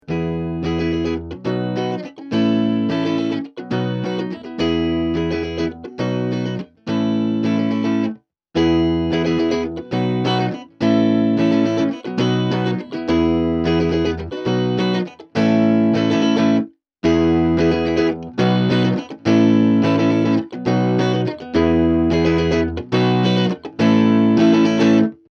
A clean boost and overdrive with excellent tone control.
Clip 2: 0% Gain, 50% Gain, 100% Gain
guitar - effect - cabinet simulator - sound card